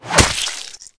monsterclaw.ogg